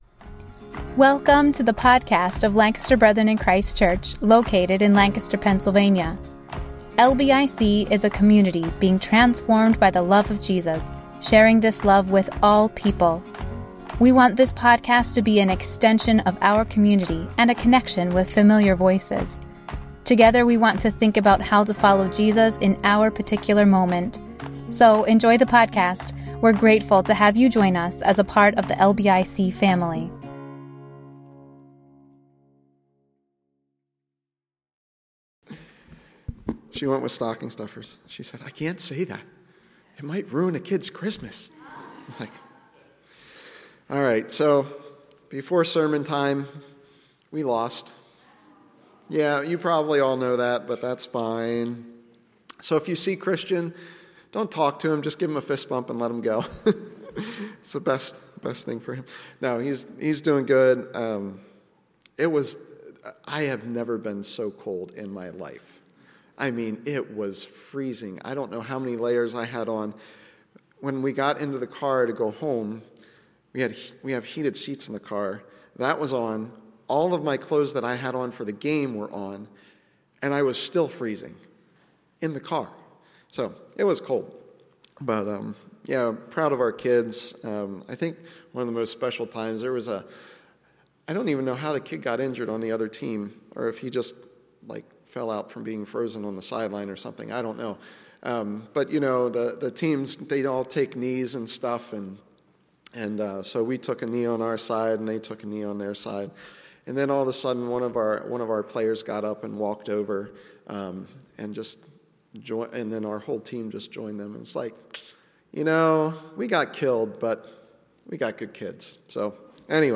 A message from the series "Advent - Awakening."